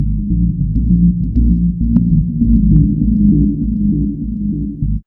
2608L B-LOOP.wav